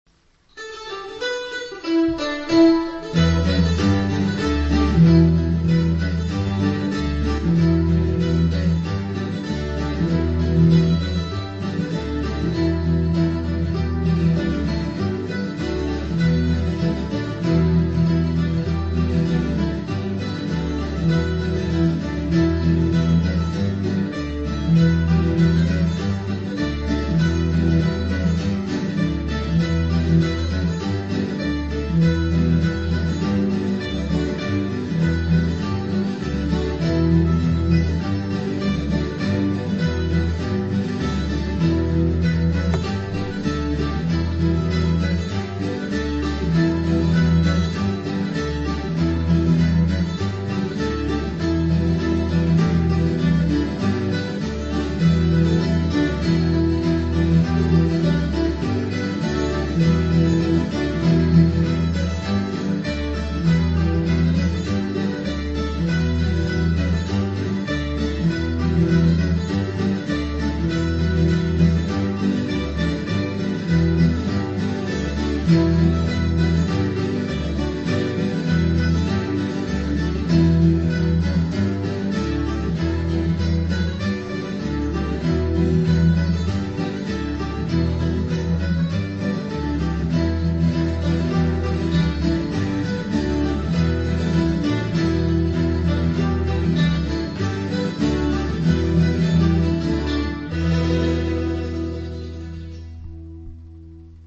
Enregistrements effectués en animation de 2012 à 2014.
Les instruments : accordéon, violons, mandoline, guitare, banjos (5 cordes américain et 4 cordes irlandais), et utilisation d'une rythmique électronique si nécessaire.
Autres instrumentaux de bal folk :
andros-bretons.mp3